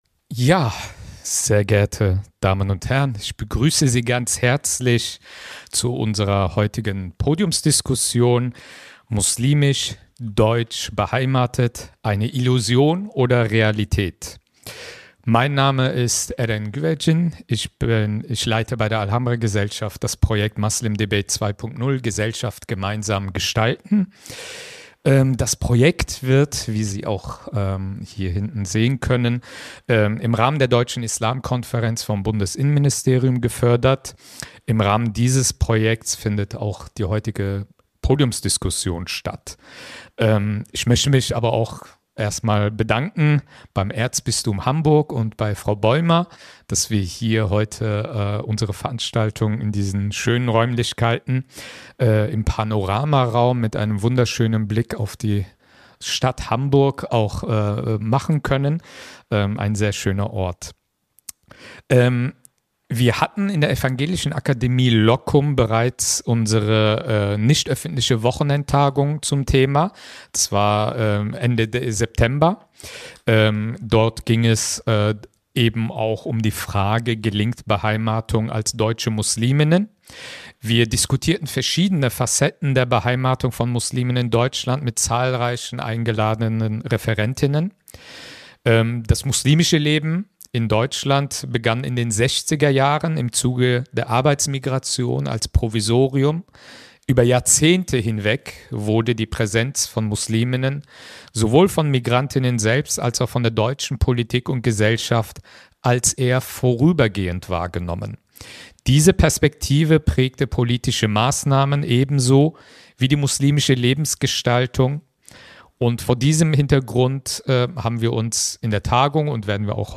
Vor dem Hintergrund der aktuellen sicherheitspolitischen und gesellschaftlichen Spannungen debattieren wir, welche konkreten Handlungsempfehlungen für Politik und muslimische Gemeinschaften nötig sind. Ein Ausblick auf zukünftige Debatten und Entwicklungen, um das muslimische Leben endlich als deutsche Normalität anzuerkennen.